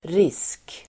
Uttal: [ris:k]